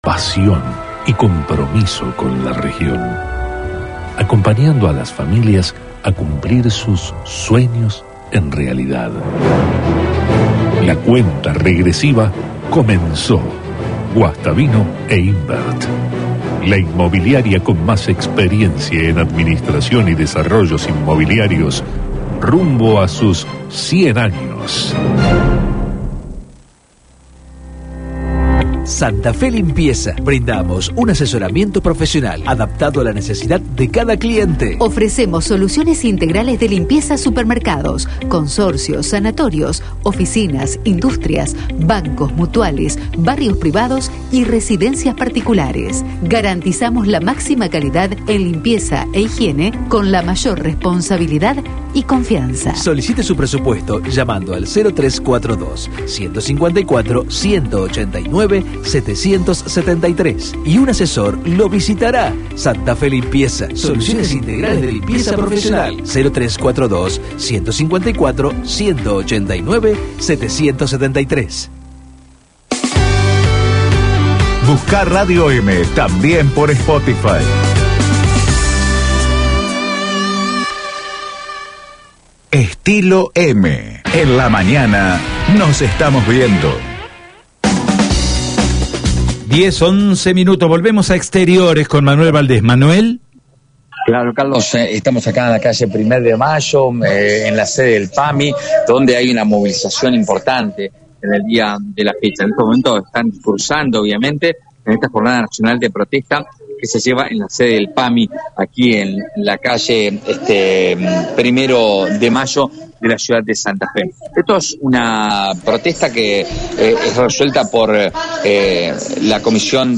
Trabajadores nucleados en ATE, junto a jubilados y representantes de otros gremios, llevaron adelante una protesta este martes en la sede del PAMI en la ciudad de Santa Fe, en el marco de una jornada nacional de reclamo.